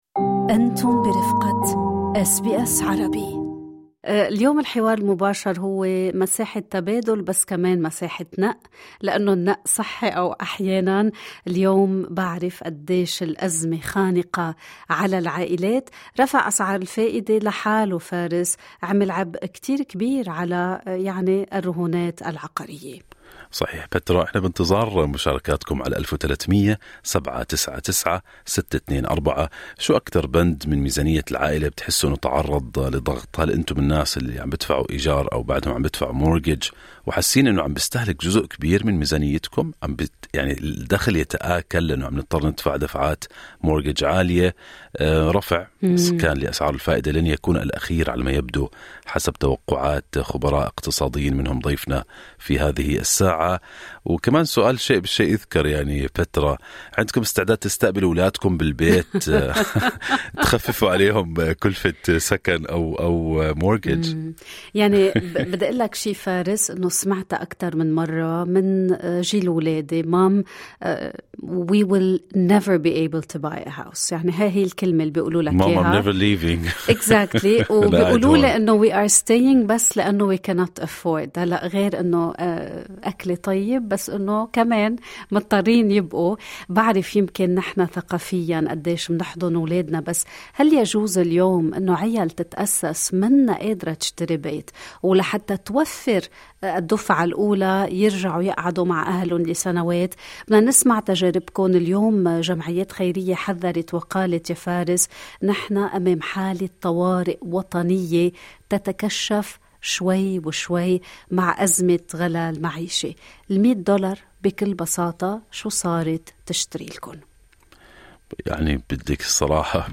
لم تعد أزمة كلفة المعيشة في أستراليا مجرد أرقام في تقارير اقتصادية، بل تحولت إلى واقع يومي يثقل كاهل الأسر. هذا ما عكسه بوضوح النقاش المفتوح ضمن برنامج Good Morning Australia، حيث شارك المستمعون تجاربهم الشخصية مع ارتفاع أسعار الفائدة، وتضخم أسعار السلع الأساسية، وضغط الإيجارات والرهون العقارية.
عدد من المستمعين تحدثوا خلال البرنامج عن التأثير المباشر لرفع أسعار الفائدة على دفعات المنازل.